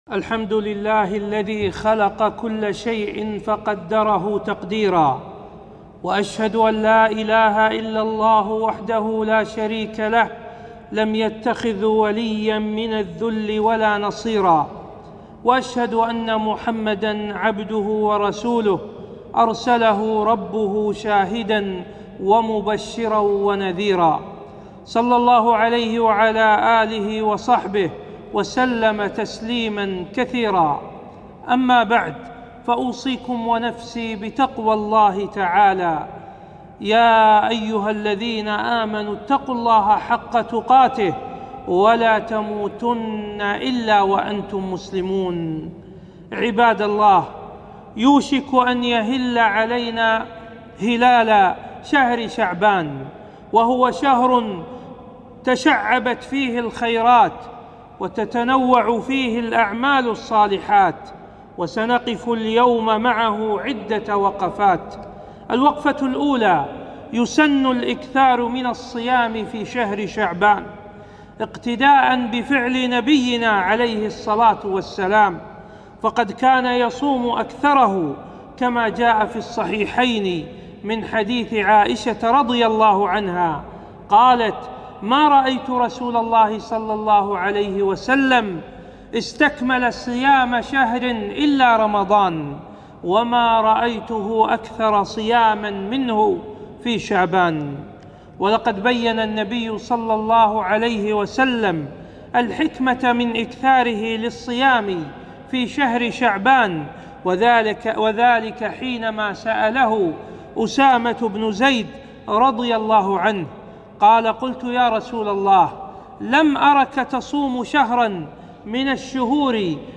خطبة - وقفات شرعية مع شهر شعبان